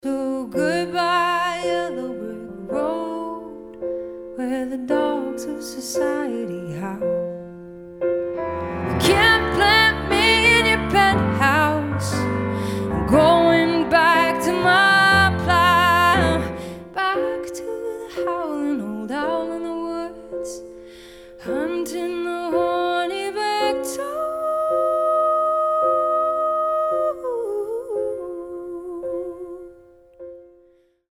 The chorus is now wistful rather than declarative.